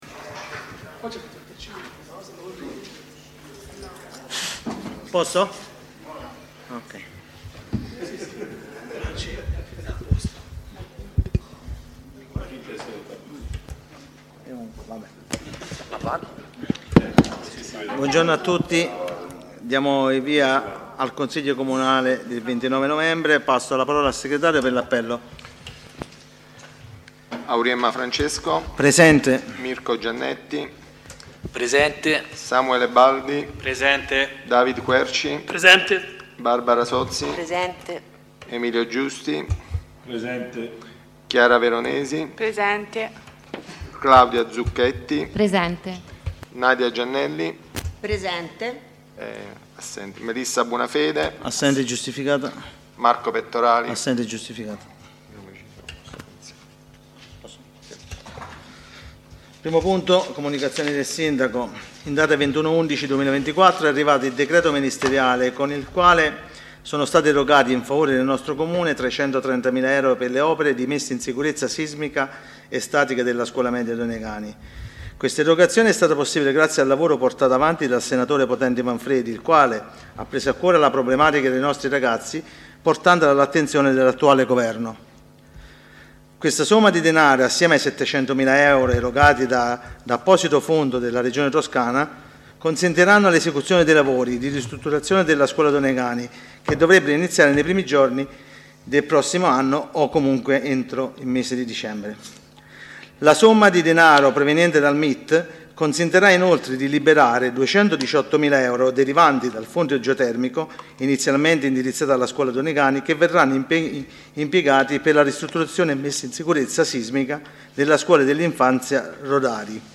Consiglio Comunale del 29/11/2024 - Comune di Montecatini Val di Cecina
download di registrazione audio della seduta